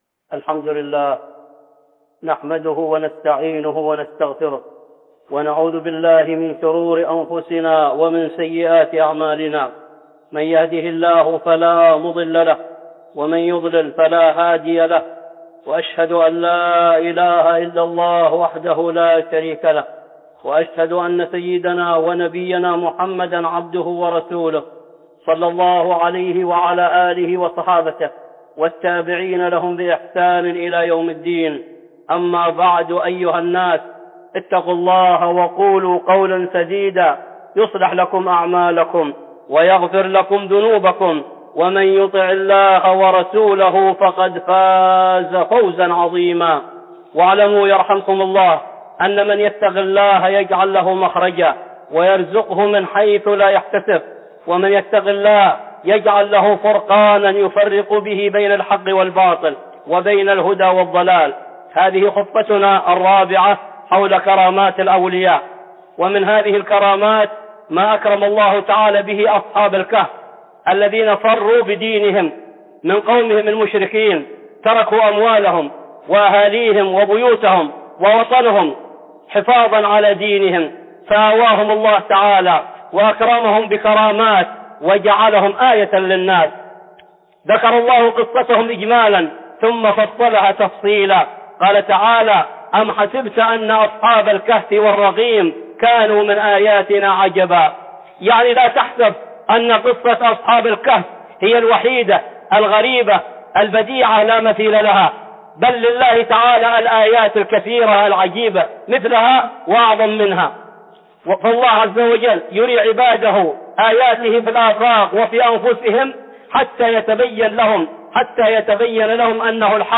(خطبة جمعة) كرامات الأولياء 4